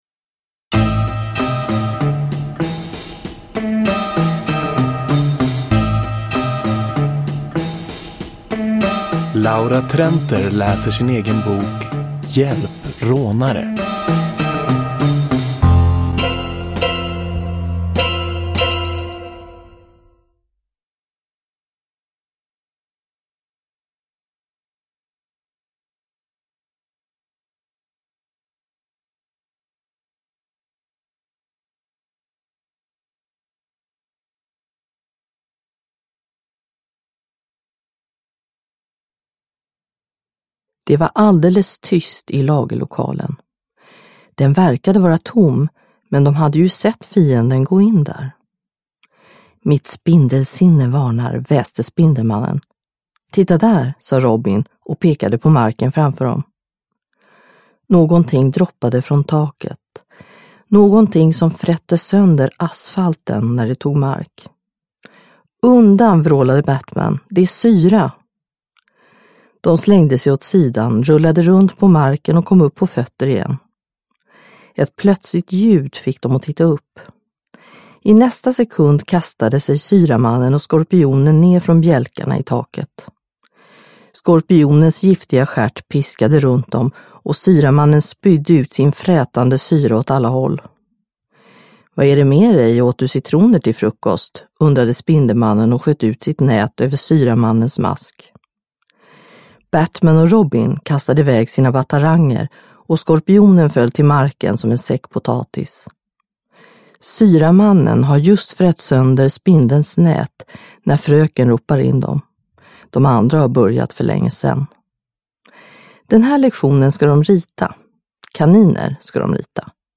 Hjälp! Rånare! – Ljudbok – Laddas ner
Uppläsare: Laura Trenter